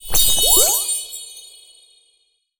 potion_heal_flask_spell_02.wav